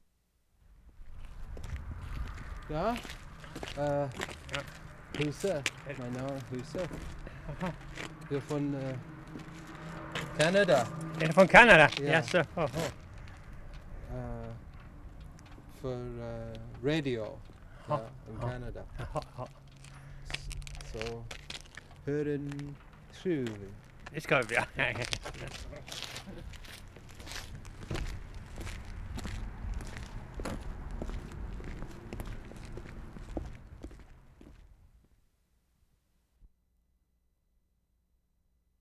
Skruv, Sweden Feb. 19/75
OUTSIDE LUMBERYARD, A SHORT CONVERSATION